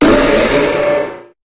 Cri de Méga-Flagadoss dans Pokémon HOME.
Cri_0080_Méga_HOME.ogg